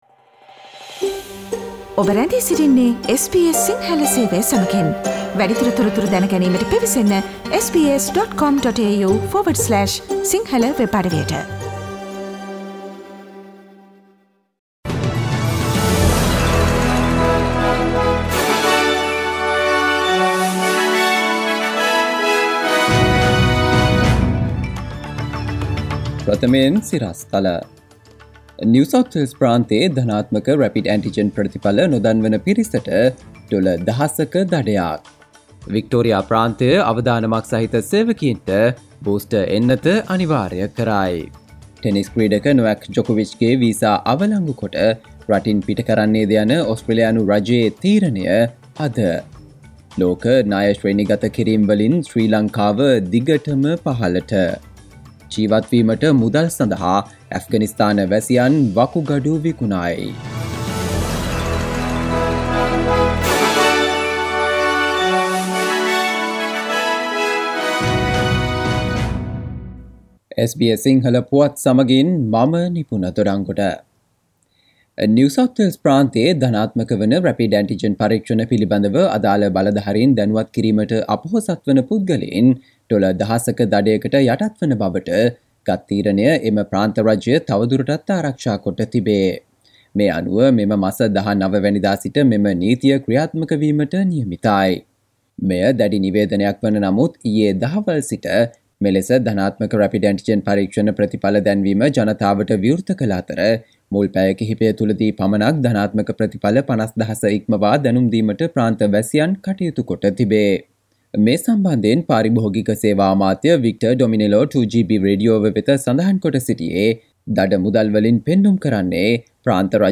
සවන්දෙන්න 2022 ජනවාරි 13 වන බ්‍රහස්පතින්දා SBS සිංහල ගුවන්විදුලියේ ප්‍රවෘත්ති ප්‍රකාශයට...